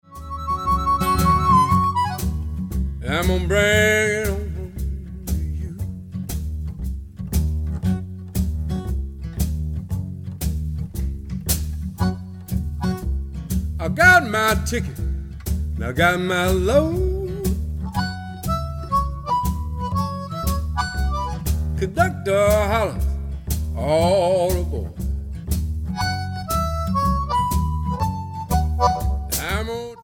Genre: Blues
Superior Audiophile Sound on XRCD24!
This is a pure analog tape recording.